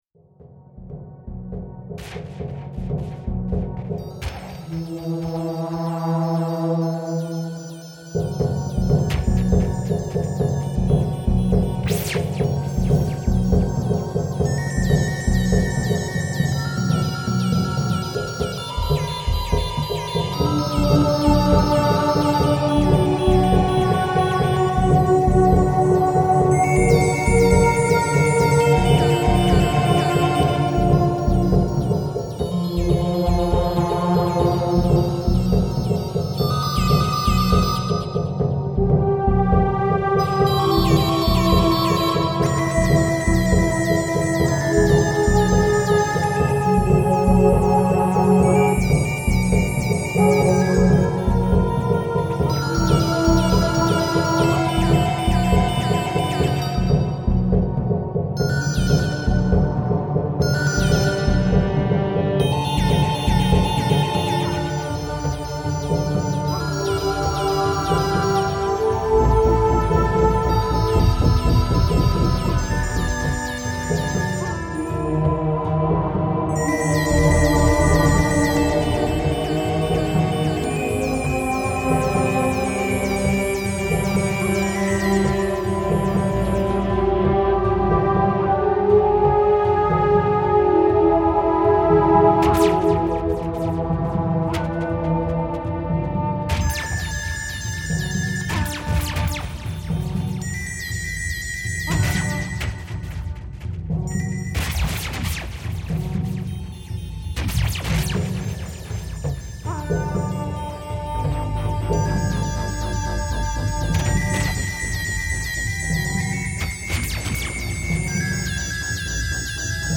Cinematic Score